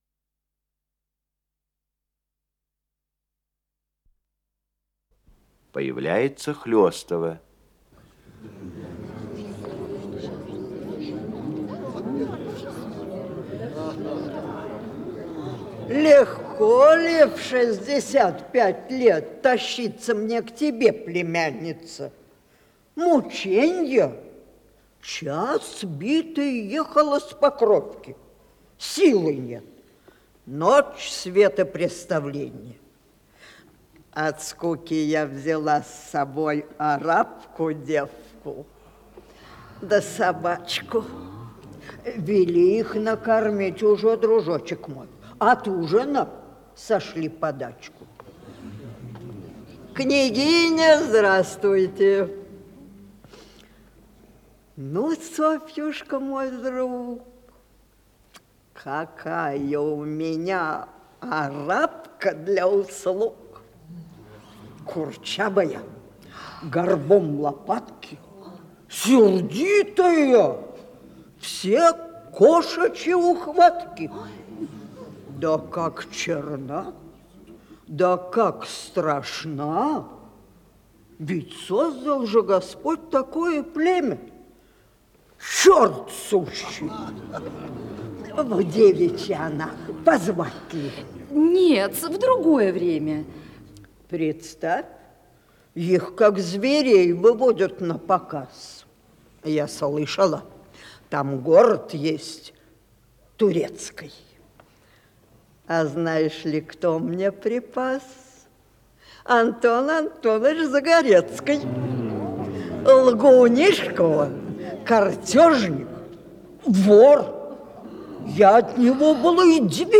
Исполнитель: Артисты Малого театра
Спектакль Государственного академического Малого театра СССР